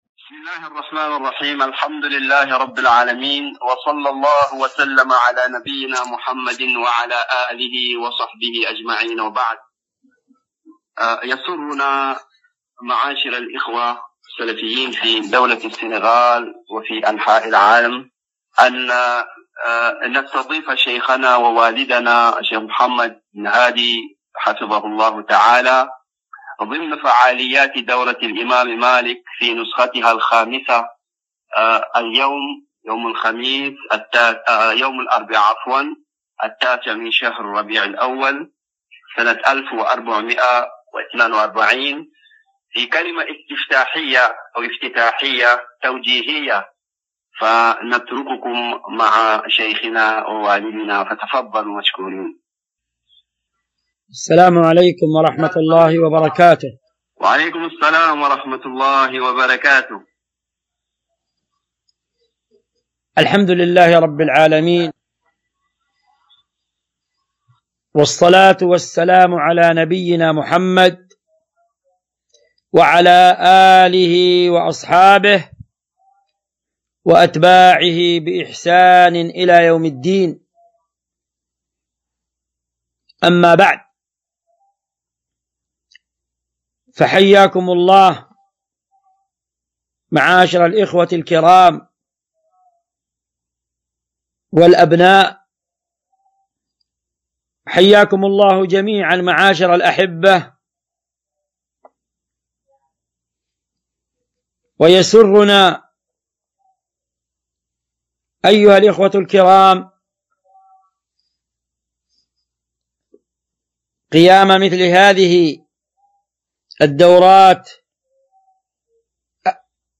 كلمة افتتاحية وجهت إلى: (دورة الإمام مالك بن أنس – رحمه الله – الخامسة)
؛| التي أُقيمت في مسجد الإمام مالك بن أنس – رحمه الله – بحي ملكا بمدينة دكار بدولة السنغال |؛